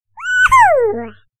задорные
Смешной звучок из маски в камере телефона.